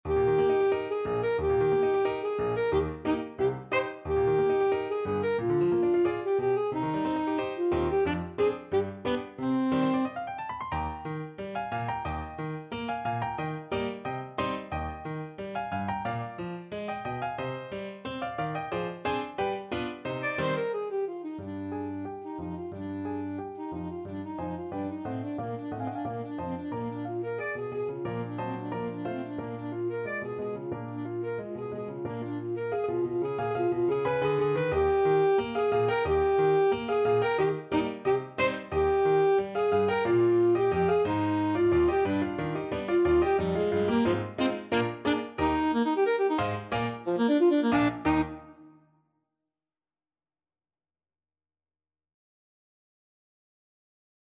Classical Brahms, Johannes Violin Concerto, Op.77, Third Movement (Main Theme) Alto Saxophone version
2/4 (View more 2/4 Music)
Eb major (Sounding Pitch) C major (Alto Saxophone in Eb) (View more Eb major Music for Saxophone )
~ = 100 Allegro giocoso, ma non troppo vivace =90 (View more music marked Allegro giocoso)
Classical (View more Classical Saxophone Music)
brahms_violin_concerto_3rd_ASAX.mp3